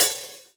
drum-hitwhistle.ogg